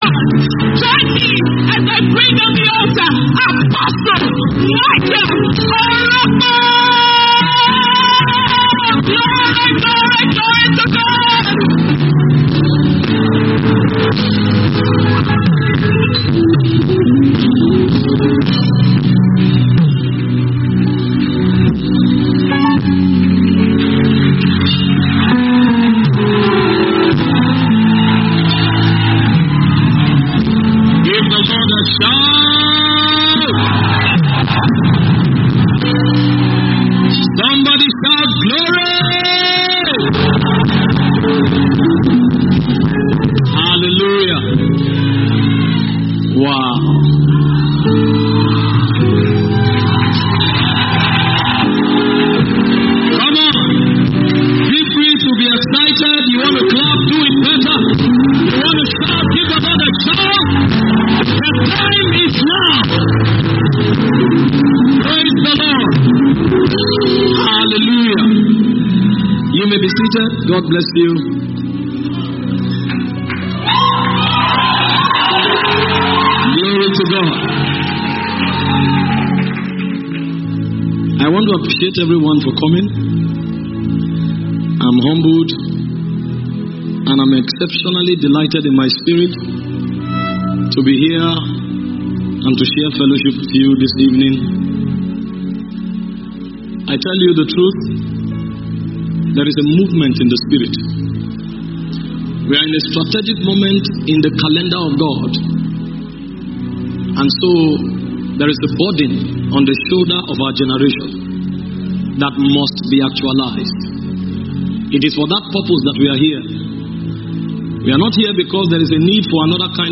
[Sermon]
Inauguration Service Live Broadcast